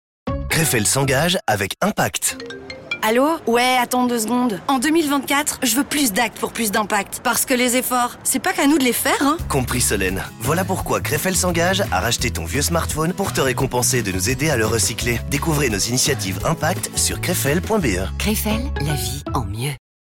Explainer